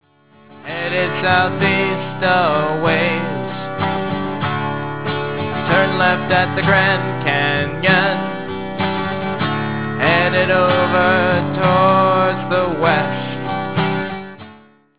Folk, Rock